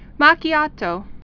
(mäkētō)